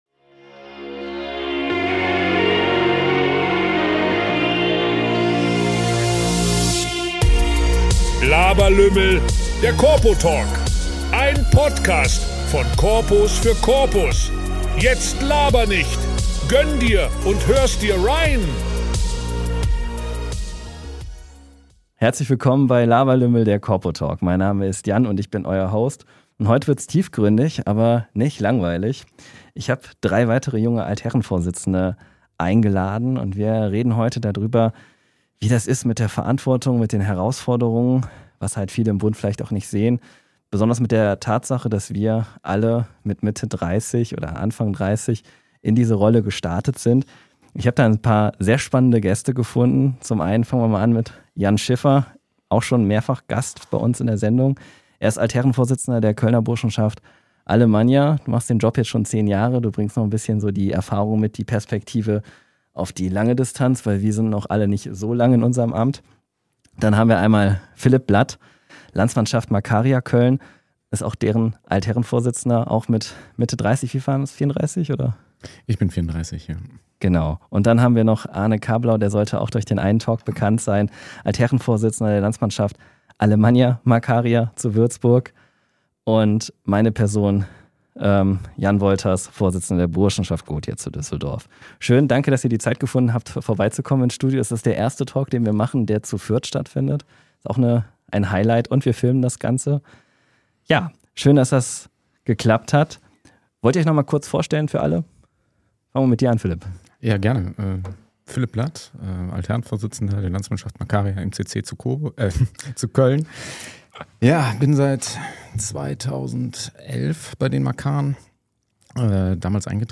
Ein spannendes Gespräch über Führung, Zweifel, Wachstum – und echte Verbindungskultur.